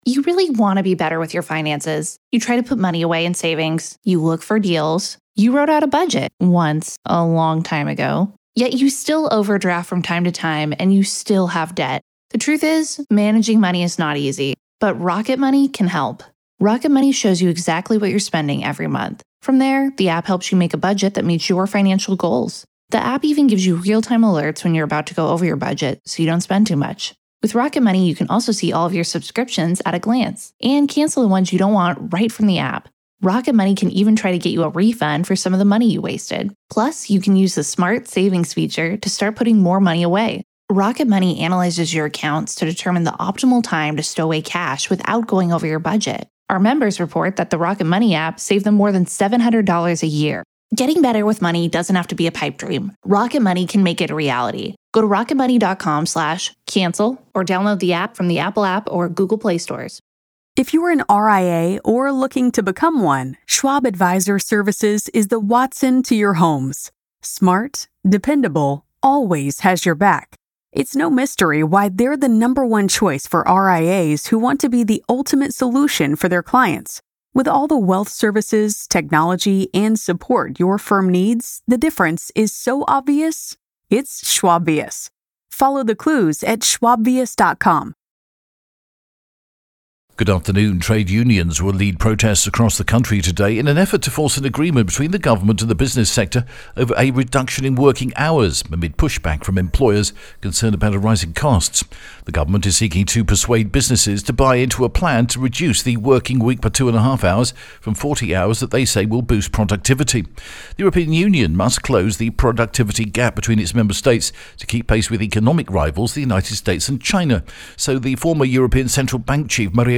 The latest Spanish news headlines in English: 26th September 2024